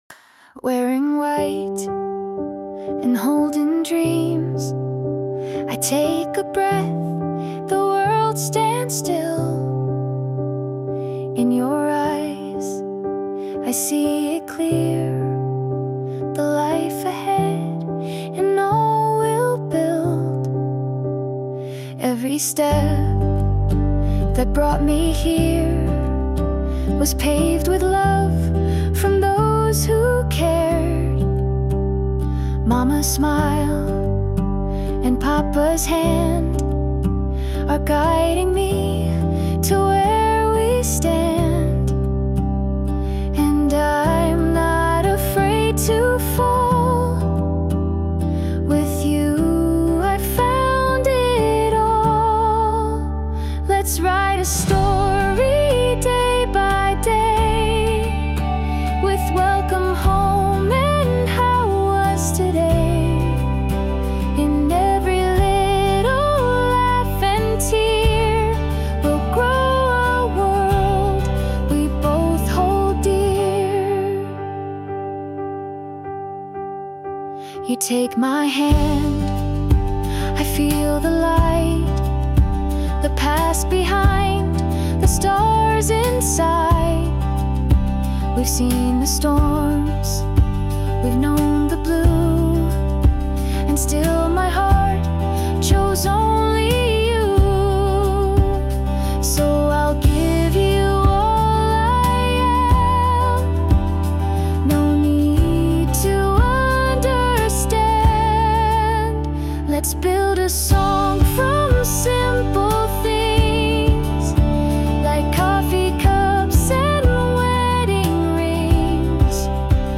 洋楽女性ボーカル著作権フリーBGM ボーカル
著作権フリーオリジナルBGMです。
女性ボーカル（洋楽・英語）曲です。
ドラマチックなバラードに乗せて歌にしました✨